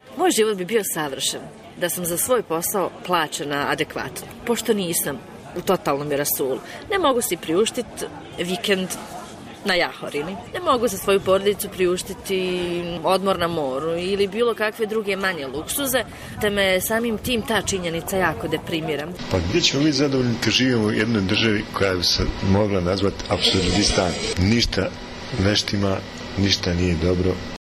U Bosni i Hercegovini su nezadovoljni i oni koji posao imaju, jer od prosječne plate od 400 eura se živjeti ne može. Brčanka i Tuzlak o tome kažu: